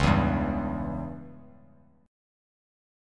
C#dim chord